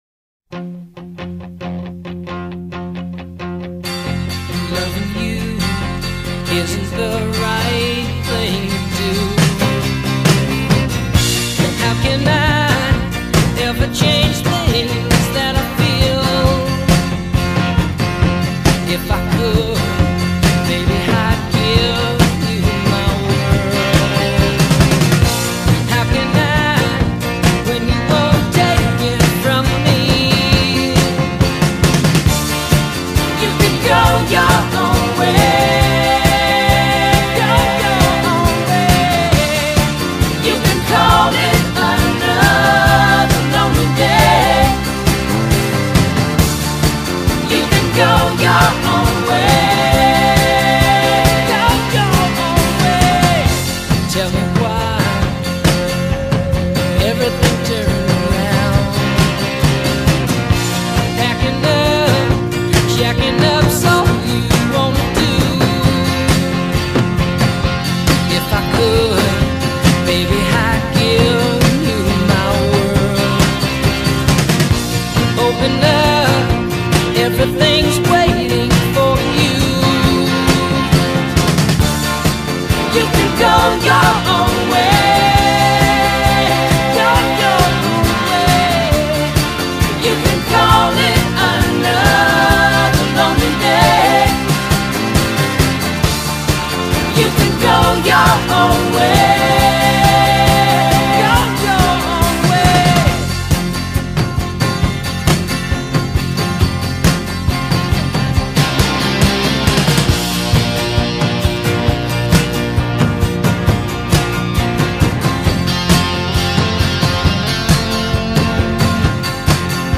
classic rock
soft rock